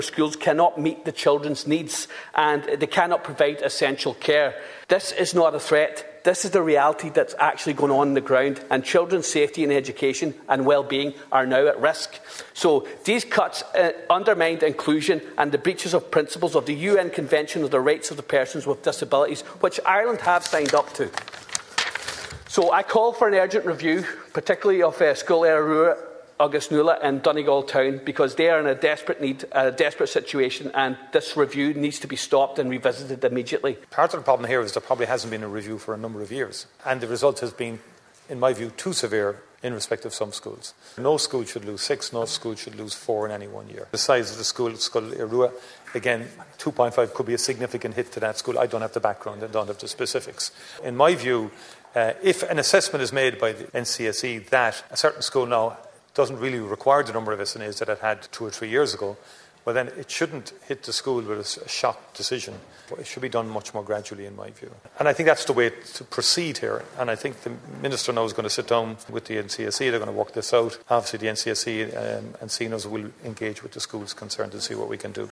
Government pauses SNA review as Ward raises concerns on the floor of Dail Eireann